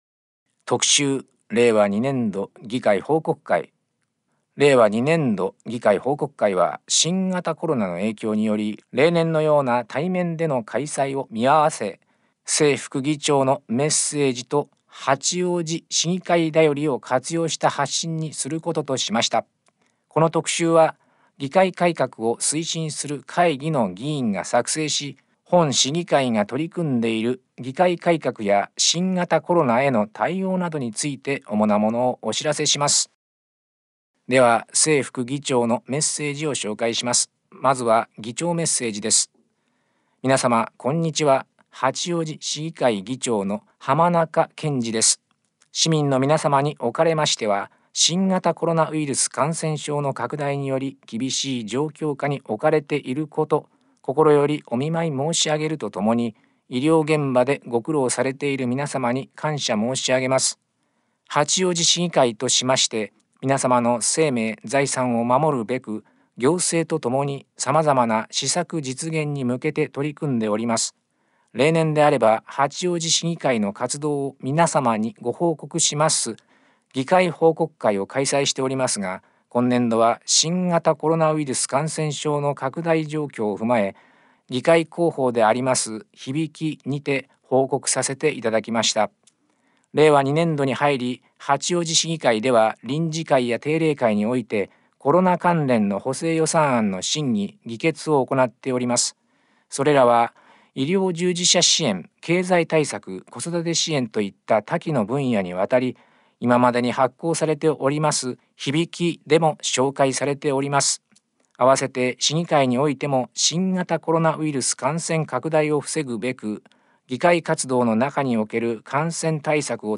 「声の市議会だより」は、視覚に障害のある方を対象に「八王子市議会だより」を再編集し、音声にしたものです。